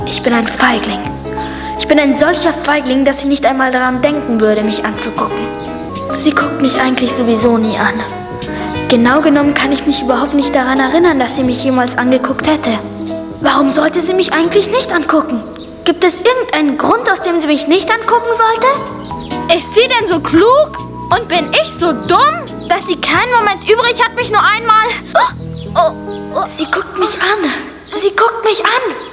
Gesang